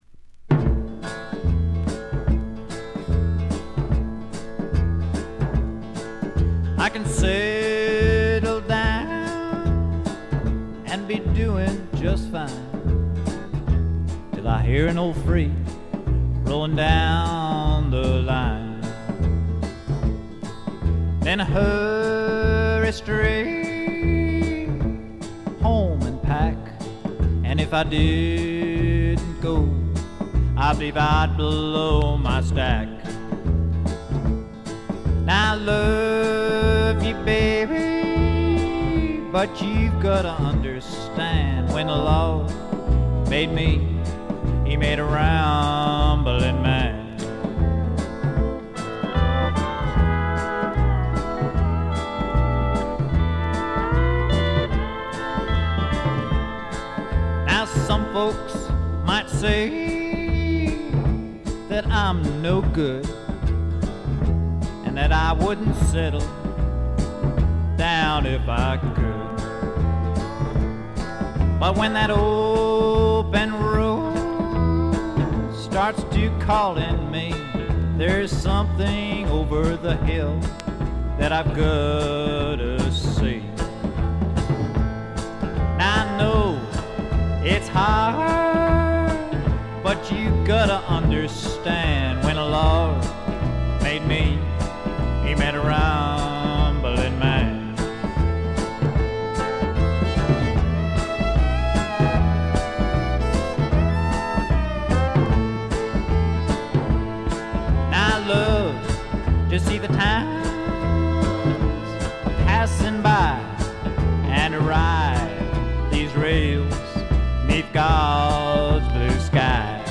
これ以外は軽微なチリプチ少々で良好に鑑賞できると思います。
試聴曲は現品からの取り込み音源です。